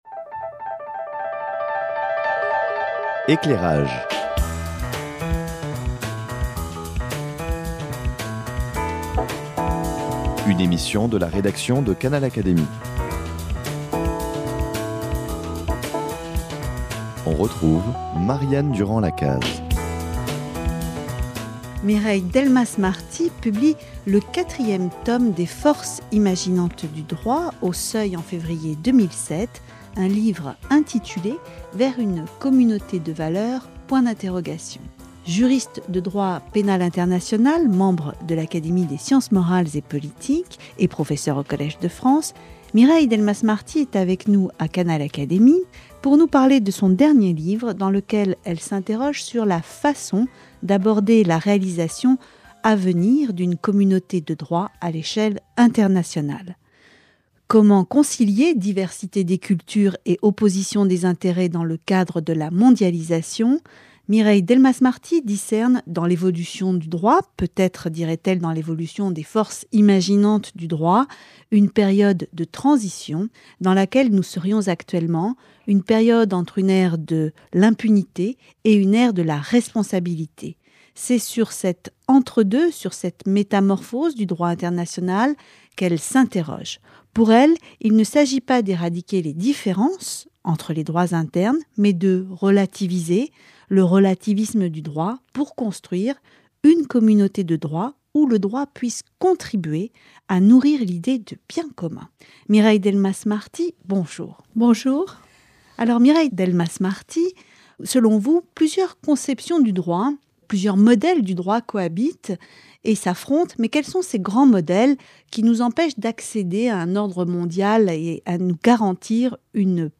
Droit et mondialisation, entretien avec Mireille Delmas-Marty : nourrir l’idée de bien commun